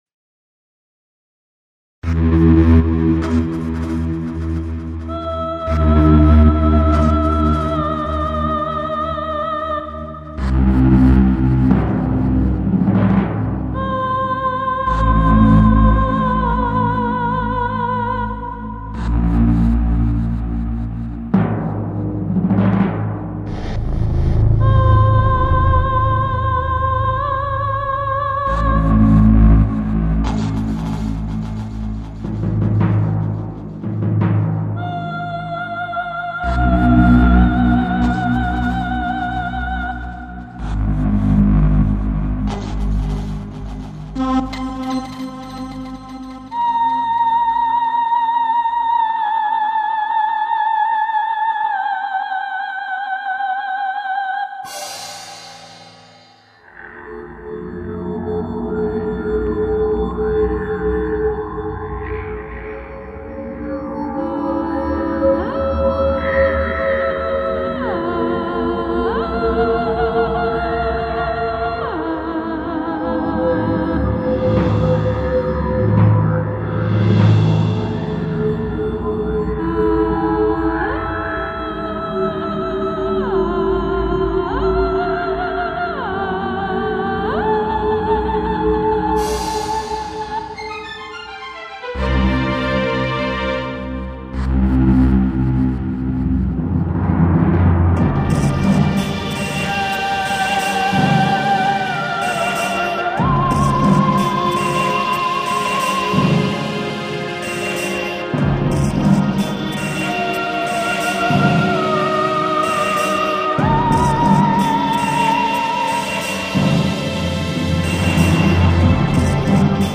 CINEMATIC MUSIC ; ILLUSTRATIVE MUSIC